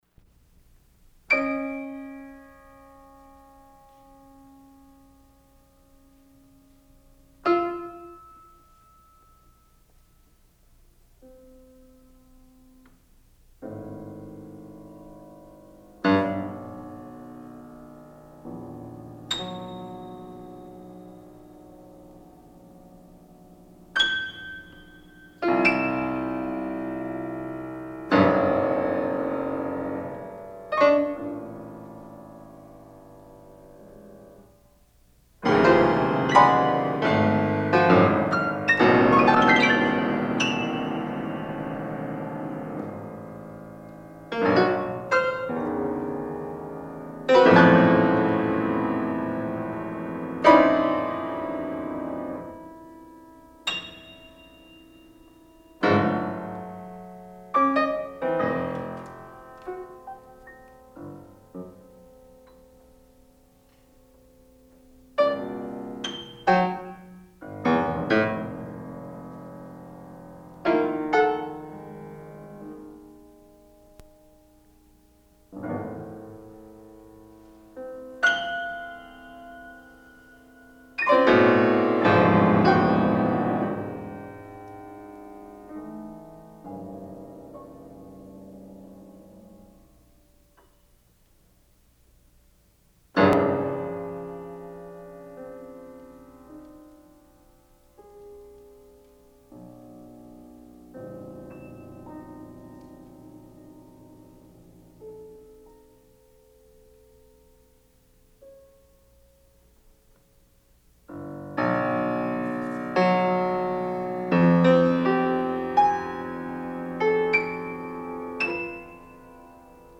unaccompanied piano